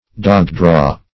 Search Result for " dogdraw" : The Collaborative International Dictionary of English v.0.48: Dogdraw \Dog"draw`\, n. (Eng. Forest Law) The act of drawing after, or pursuing, deer with a dog.